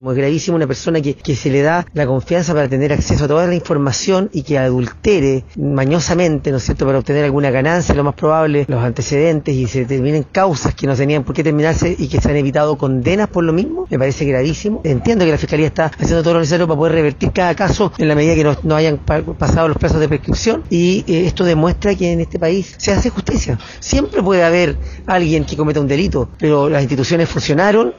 El jefe regional, Leonardo de la Prida, calificó el hecho como grave y respaldó el trabajo de la Fiscalía.